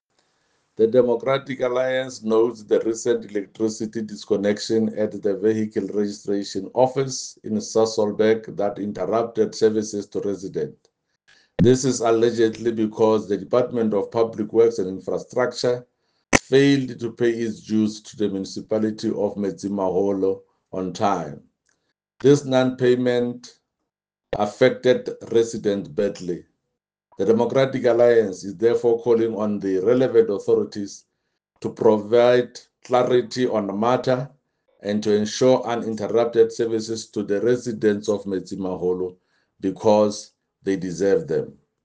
Sesotho soundbites by Jafta Mokoena MPL